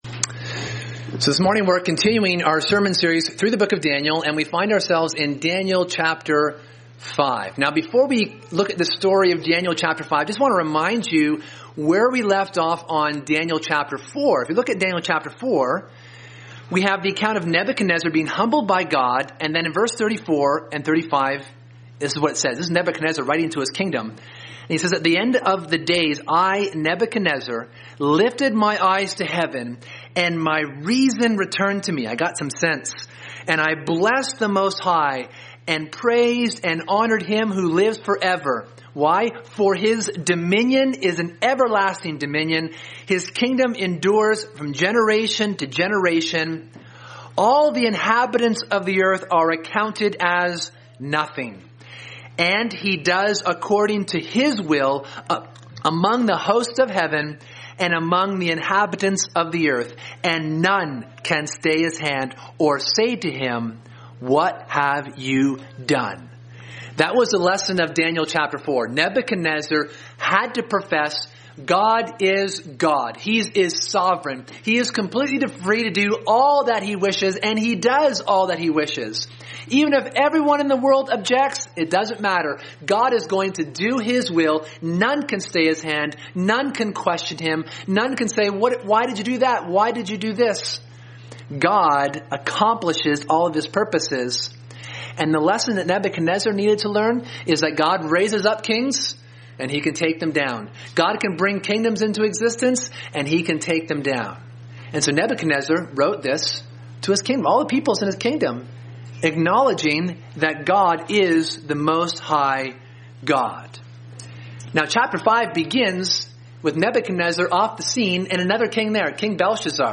Sermon: The Writing on the Wall